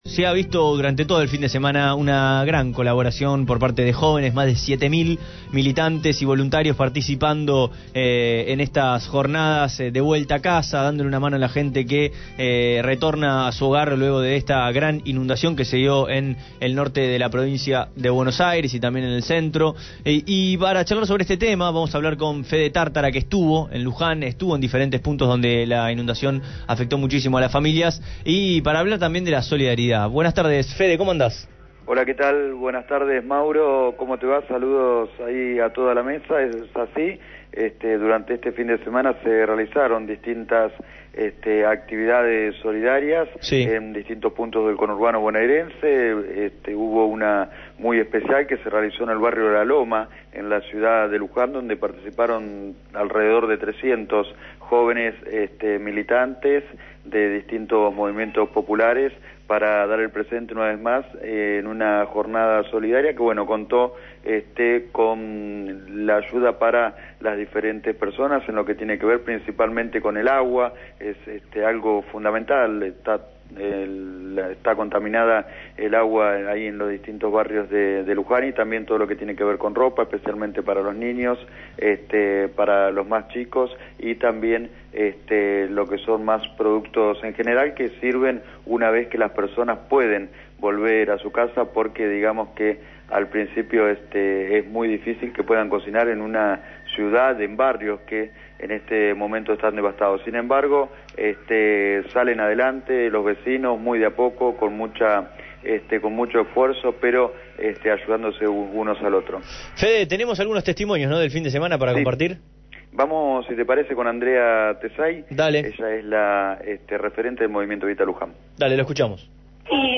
Luján